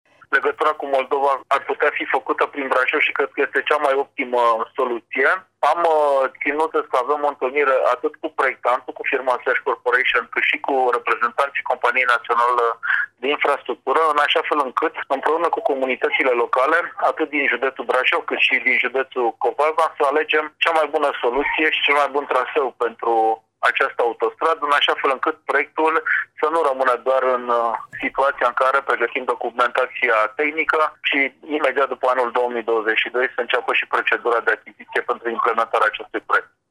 Presedintele Consiliului Judetean Brasov, Adrian Vestea: